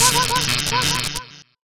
shock.wav